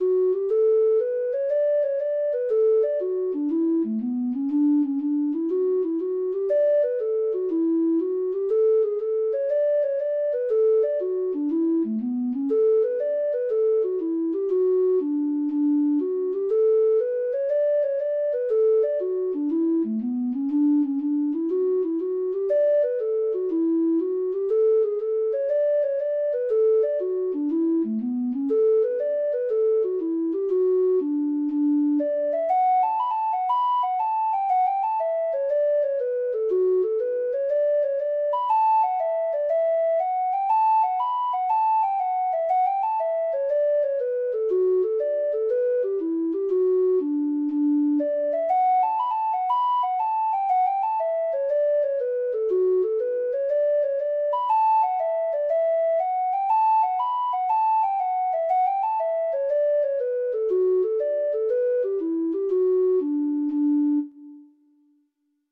Treble Clef Instrument version
Traditional Music of unknown author.